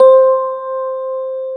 Index of /90_sSampleCDs/Syntec - Wall of Sounds VOL-2/JV-1080/SMALL-PIANO